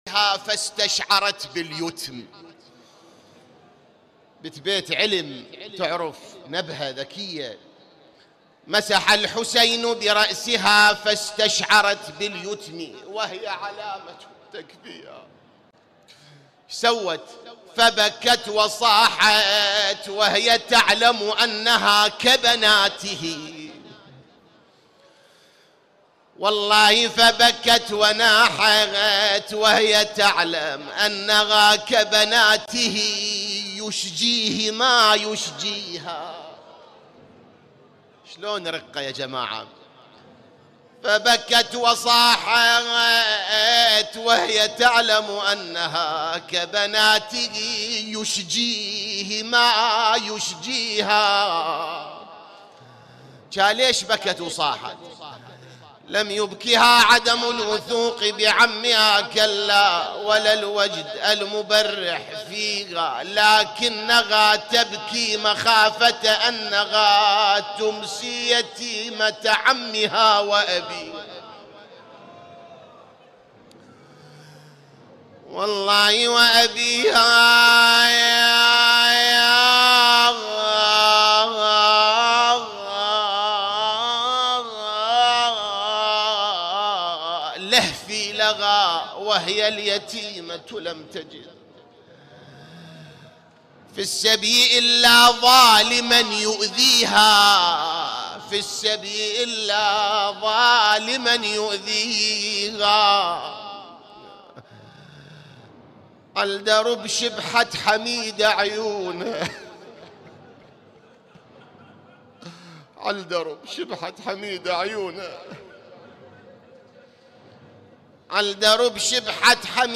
◼ليلة 5 محرم 1447 هـ || من الصحن الحسيني ||